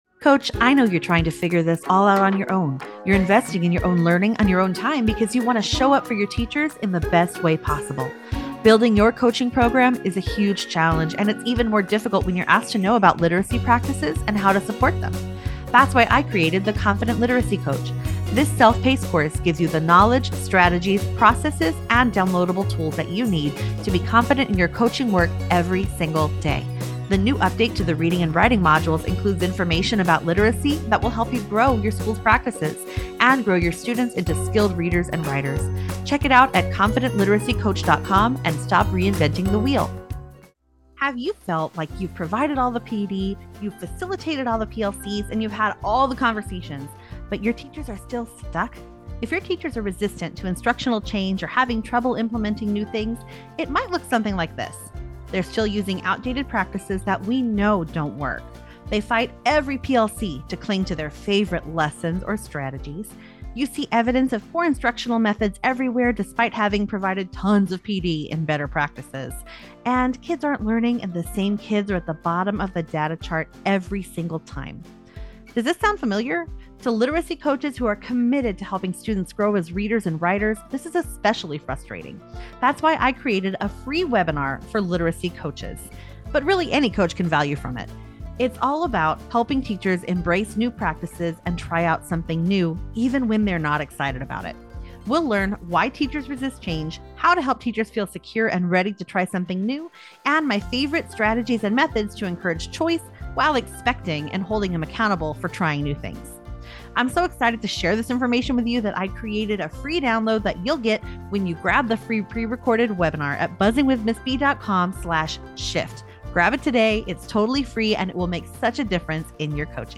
Listen now to this thought-provoking interview about the art of transformational coaching.